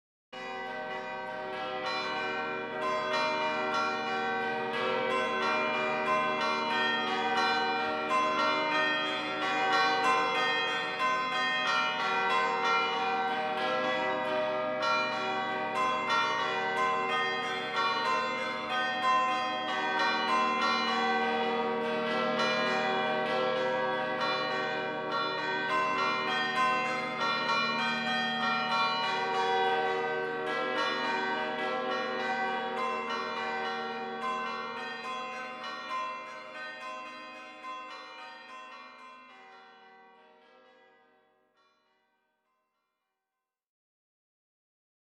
Bells – Carillon d’Hérémence